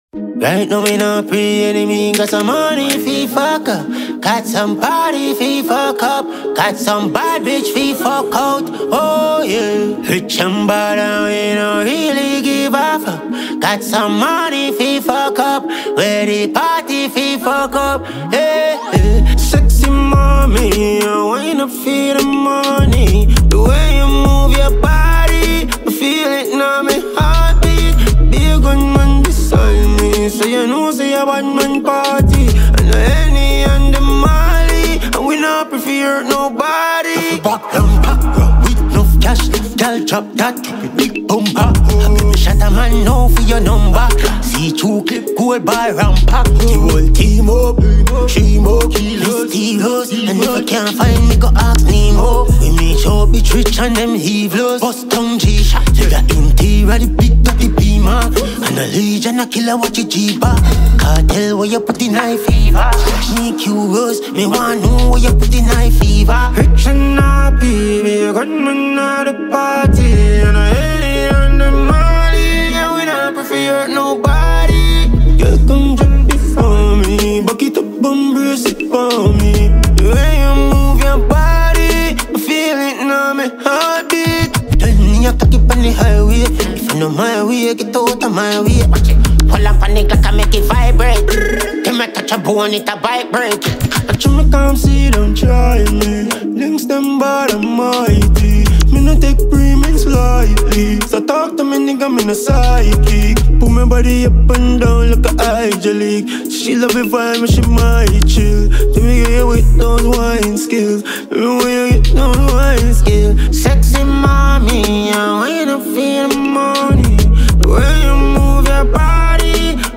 DANCEHALL
dark, melodic dancehall style
Its tight structure, catchy hook, and pulsating rhythm
delivering a bold sound that fans of his darker